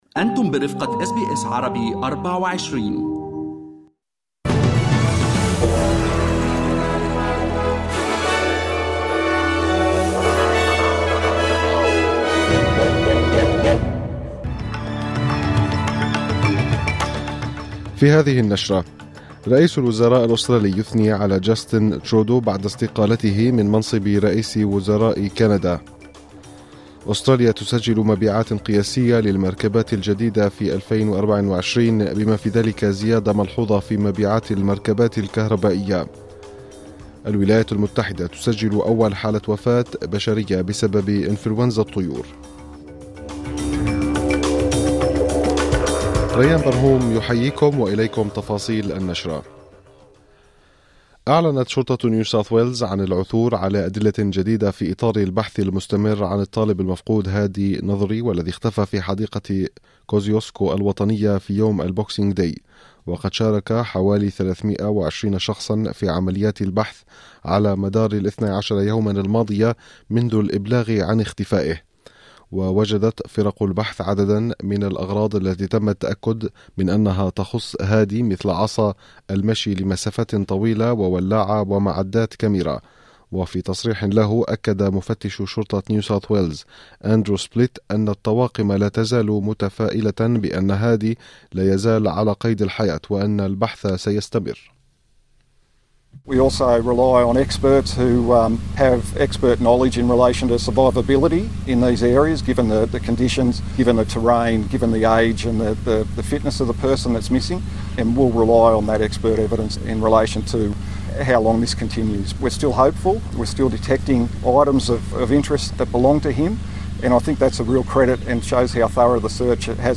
نشرة أخبار المساء 07/01/2025